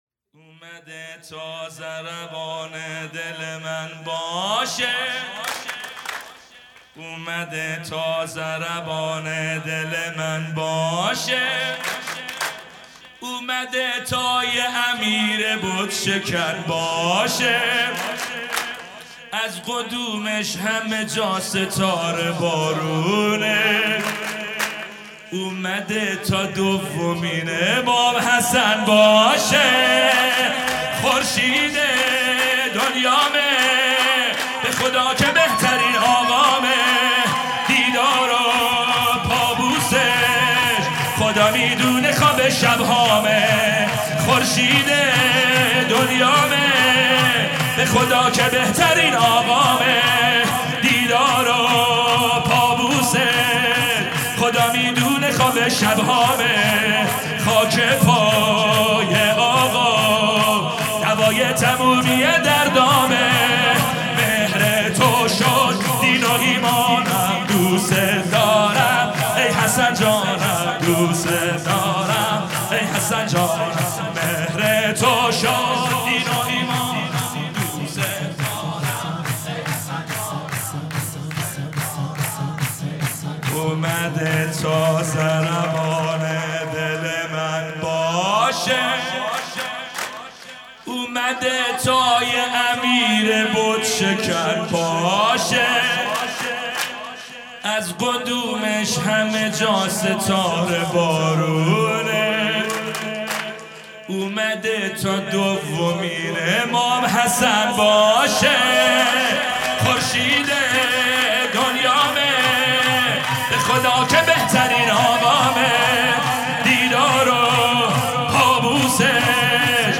سرود
ولادت امام حسن عسکری (ع) | ۲۴ آذر ۱۳۹۷